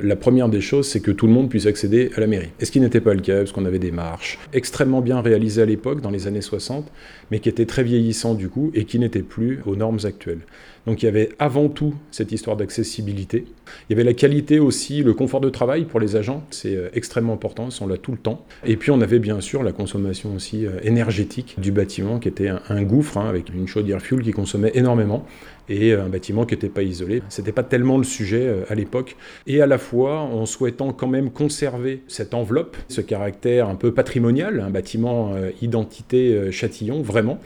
La mairie rénovée sera officiellement inaugurée à 11h, même si c’est en fait depuis fin mars dernier que les services municipaux et l’agence postale ont réintégré le bâtiment d’origine, après de longs mois de travaux. Mais ce chantier de réhabilitation était devenu nécessaire comme l’explique le maire sortant Cyril Cathelineau.